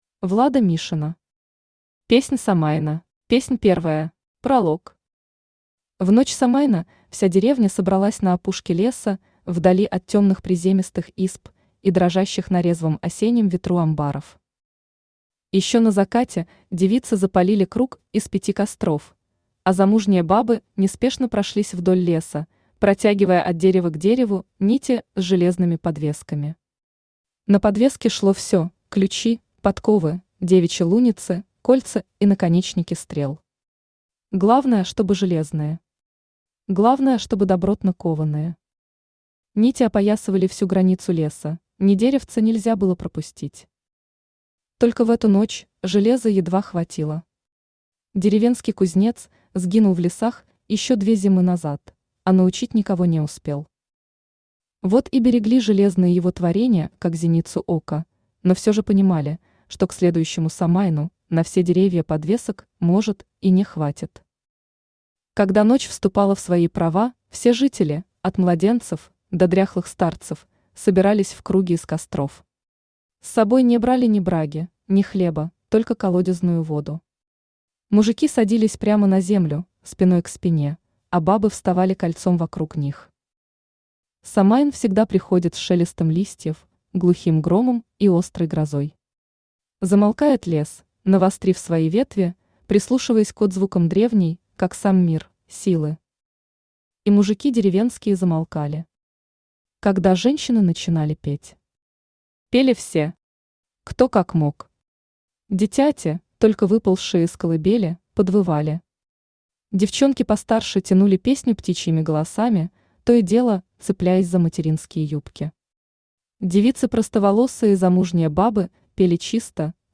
Аудиокнига Песнь Самайна | Библиотека аудиокниг
Aудиокнига Песнь Самайна Автор Влада Владимировна Мишина Читает аудиокнигу Авточтец ЛитРес.